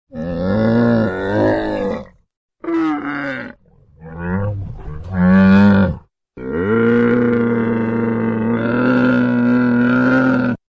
Звуки верблюда
Мычит